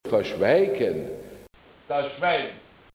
Vergleich „(Ver-)Schweigen“ bei van Staa und „Schwein“ bei van Staa